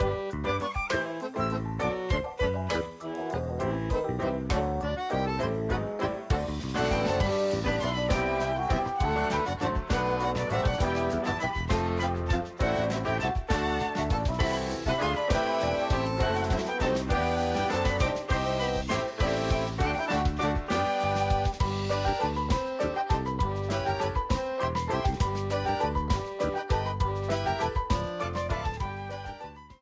A red streamer theme
Ripped from the game
clipped to 30 seconds and applied fade-out